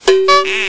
SFX尴尬dong zi a 000音效下载
SFX音效